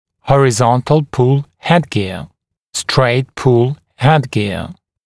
[ˌhɔrɪ’zɔntl-pul ‘hedgɪə] [streɪt-pul ‘hedgɪə][ˌхори’зонтл-пул ‘хэдгиа] [стрэйт-пул ‘хэдгиа]лицевая дуга с горизонтальной тягой